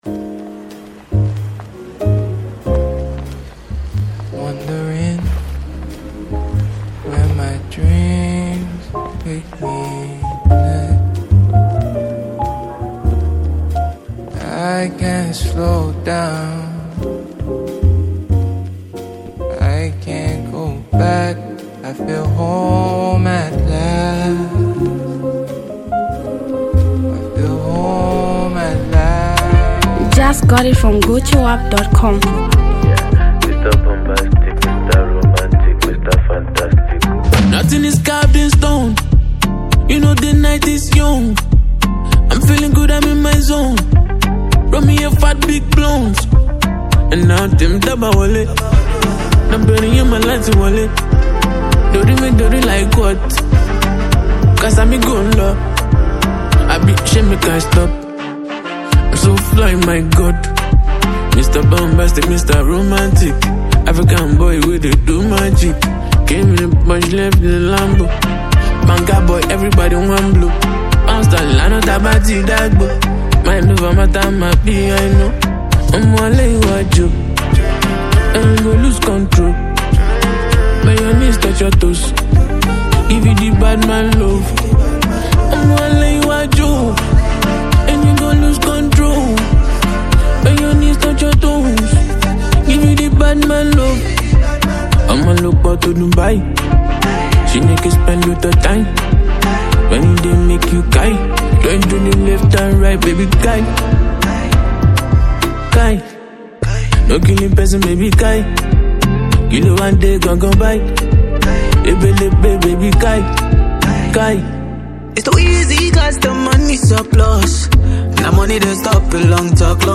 a Nigerian rap sensation
diss track vibes